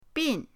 bin4.mp3